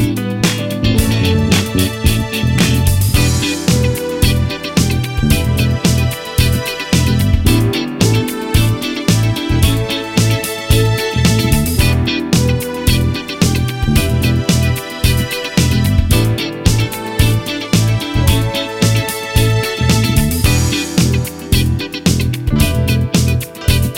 no kit bass or main guitar Disco 3:34 Buy £1.50